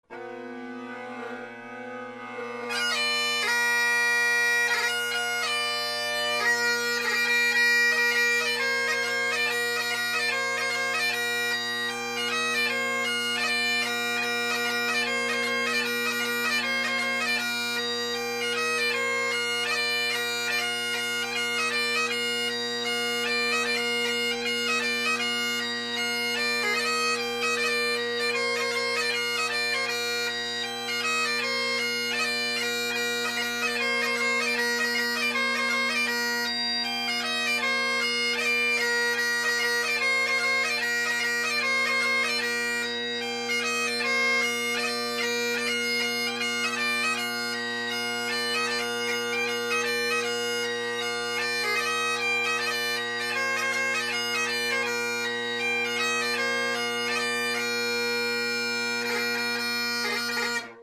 Kate Dalrymple is a great little 2 part reel that reinforces common reel basics.